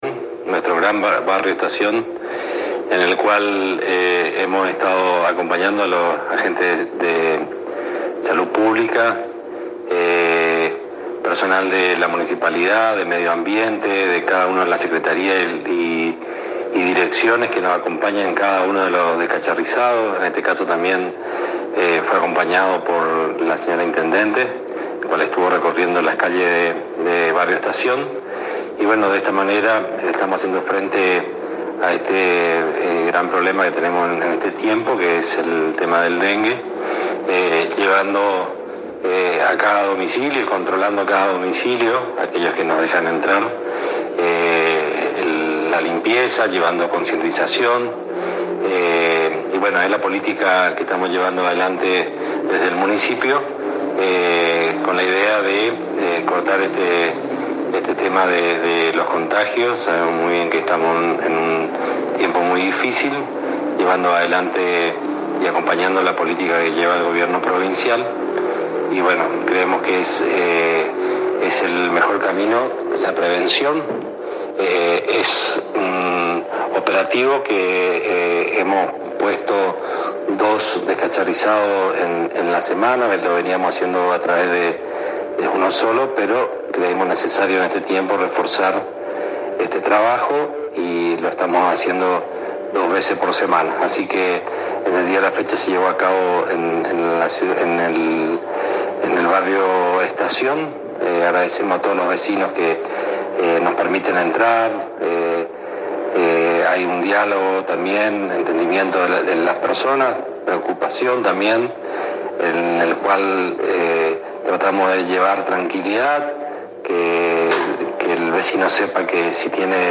Director Medio Ambiente Alberto Daniel Poliszuk